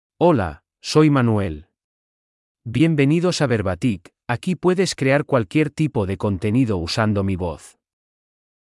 MaleSpanish (Cuba)
Manuel — Male Spanish AI voice
Manuel is a male AI voice for Spanish (Cuba).
Voice sample
Manuel delivers clear pronunciation with authentic Cuba Spanish intonation, making your content sound professionally produced.